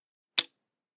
Звуки банковской карты
Звук упавшей банковской карты на поверхность